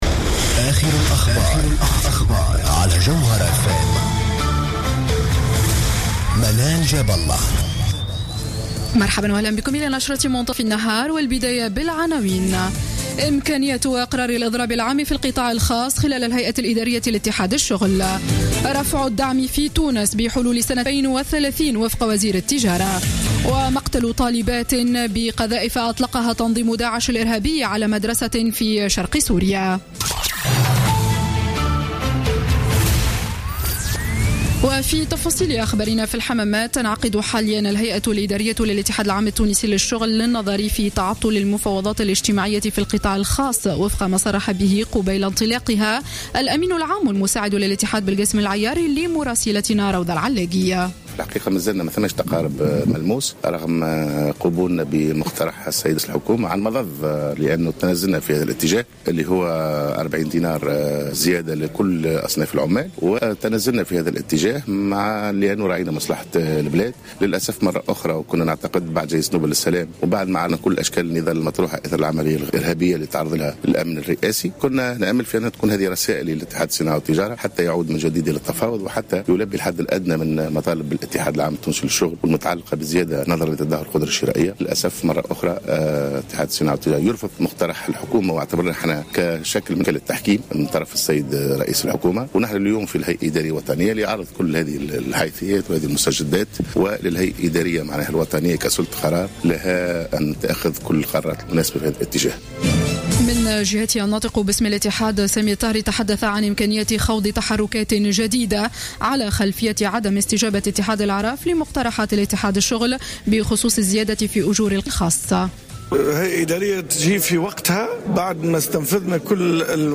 نشرة أخبار منتصف النهار ليوم الثلاثاء 22 ديسمبر 2015